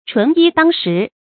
鶉衣鷇食 注音： ㄔㄨㄣˊ ㄧ ㄎㄡˋ ㄕㄧˊ 讀音讀法： 意思解釋： 謂衣不蔽體，食不果腹。